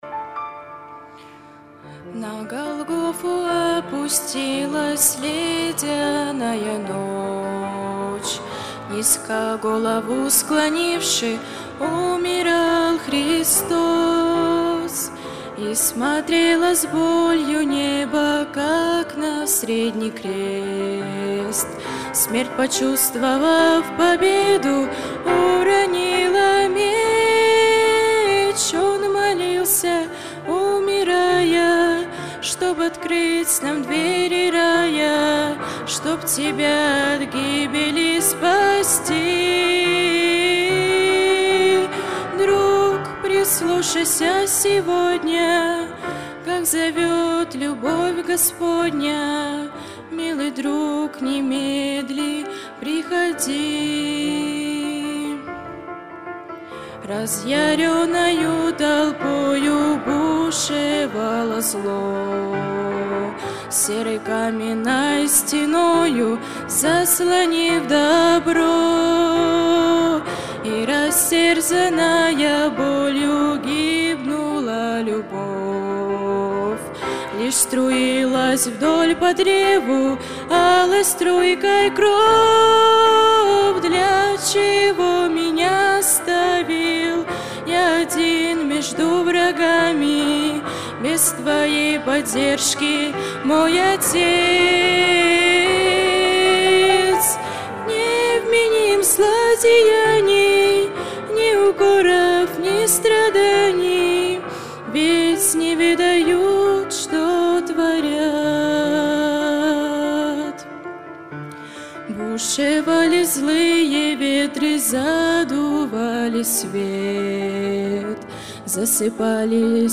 Богослужение 06.10.2024
Пение